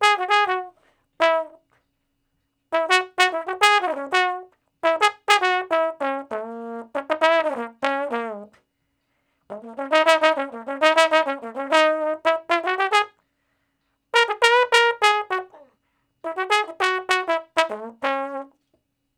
099 Bone Straight (Db) 02.wav